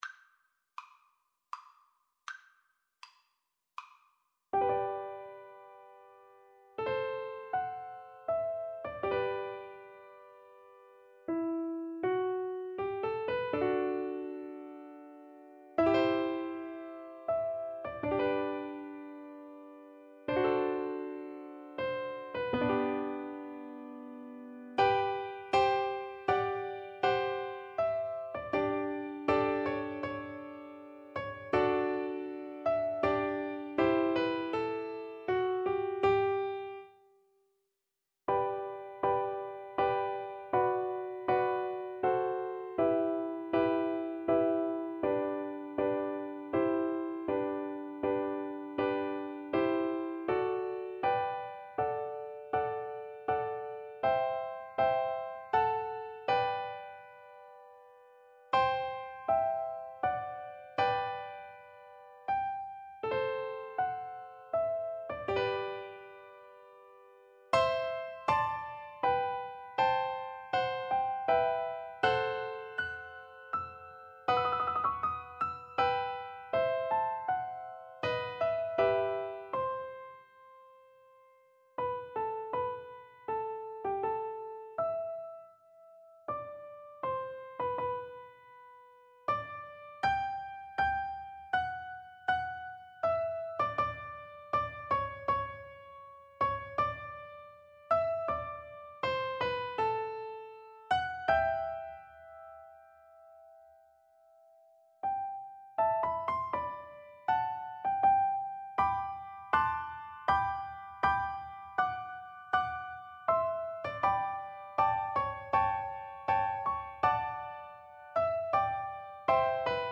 Free Sheet music for Piano Four Hands (Piano Duet)
Andante grandioso
3/4 (View more 3/4 Music)
Classical (View more Classical Piano Duet Music)